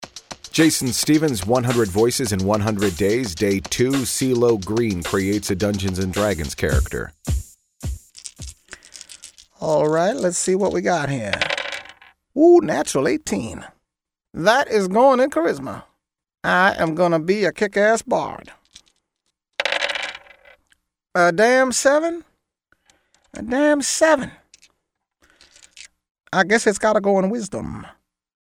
But here’s my Cee Lo Green impression, as he rolls up a D&D character.
Tags: 100 Voices 100 Days, Cee Lo Green impersonation